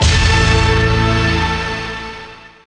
68_14_stabhit-A.wav